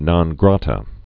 (nŏn grätə, grătə, nōn)